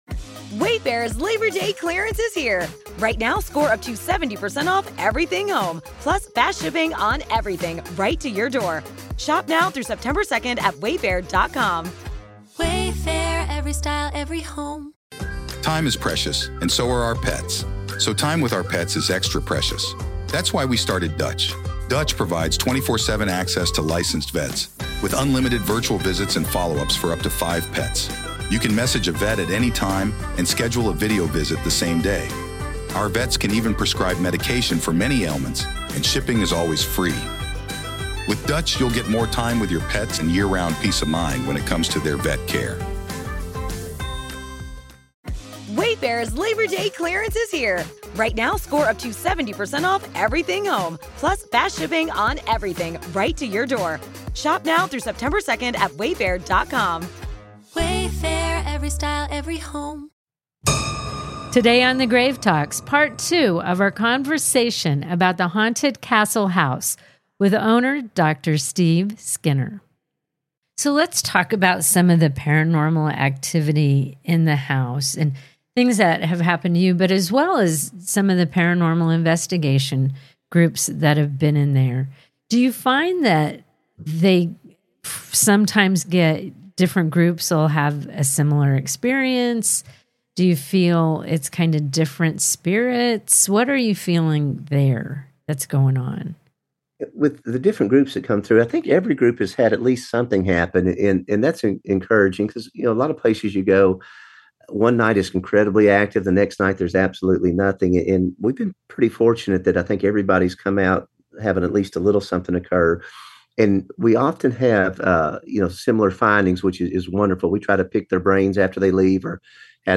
The house has seen lots of trauma, tragedy, and death…which could account for the apparitions, noises, knocks, voices, and all of the paranormal activity under its roof. Today on the Grave Talks, Part Two of our conversation about the Haunted Castle House